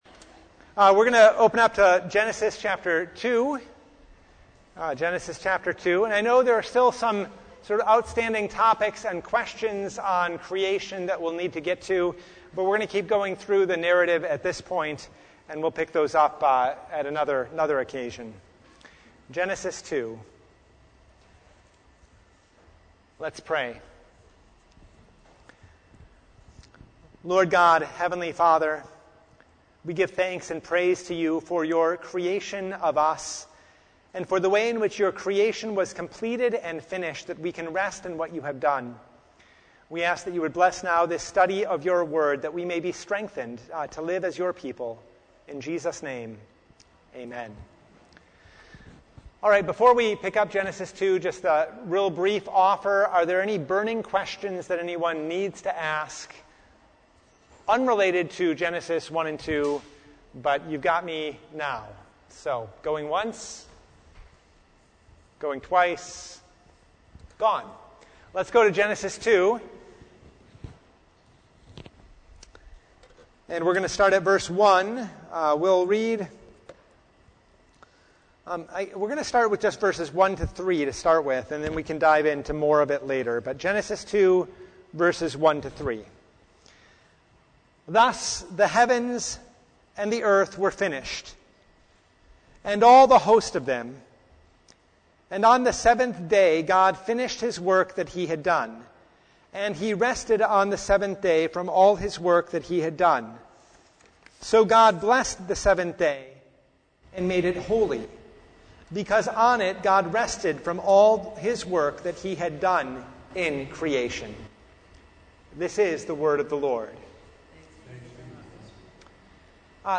Genesis 2:1-3 Service Type: Bible Hour Topics: Bible Study « The First Sunday in Angels’ Tide